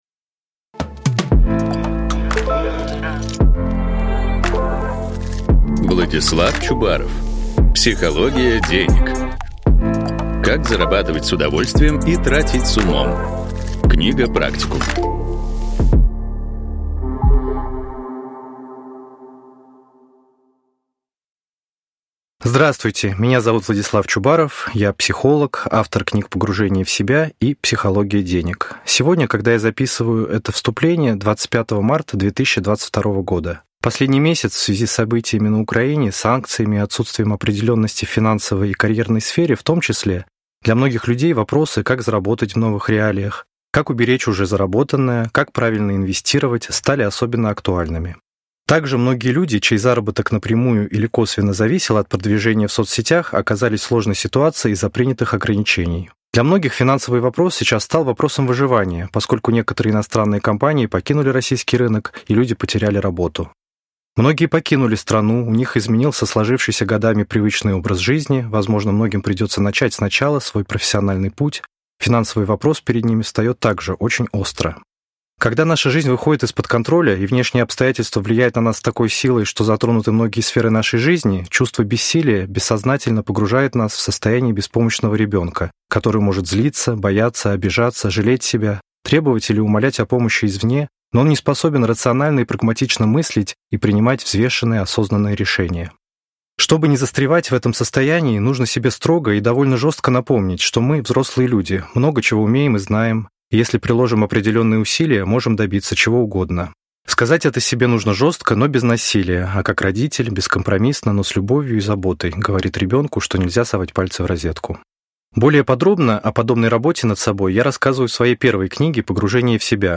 Аудиокнига Психология денег. Как зарабатывать с удовольствием и тратить с умом. Книга-практикум | Библиотека аудиокниг
Прослушать и бесплатно скачать фрагмент аудиокниги